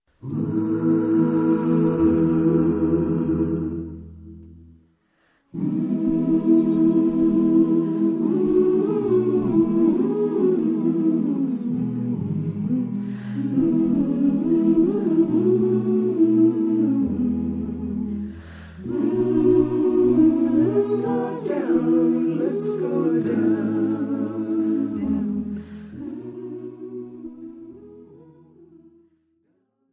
This is an instrumental backing track cover.
• Key – E
• With Backing Vocals
• No Fade